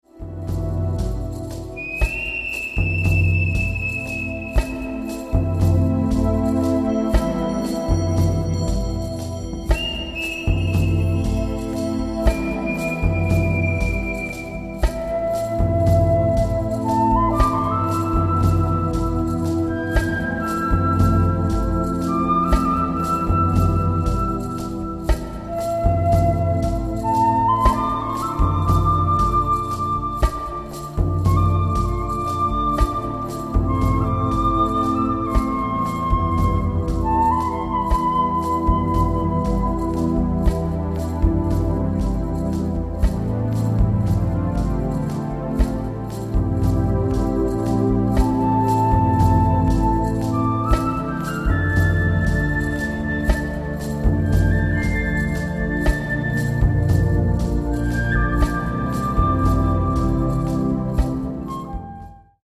Sound Track
SYNTHESIZER
PERCUSSIONS